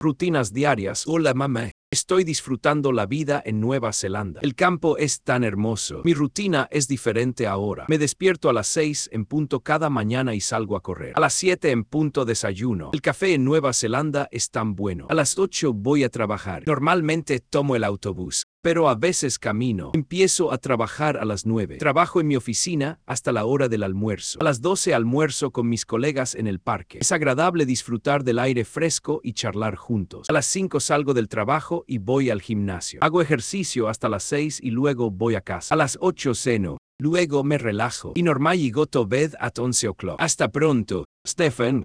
dailyrutines.kokoro.dub.es.wav